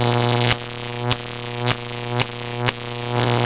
arcloop.wav